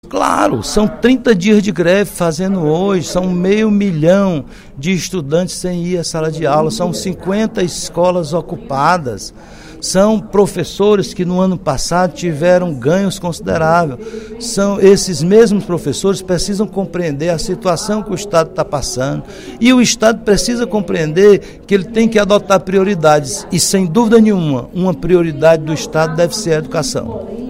O deputado Roberto Mesquita (PSD) fez pronunciamento nesta sexta-feira (20/05), no segundo expediente da sessão plenária, para defender que o Poder Legislativo faça uma mediação entre o Estado e os professores das escolas públicas em grave.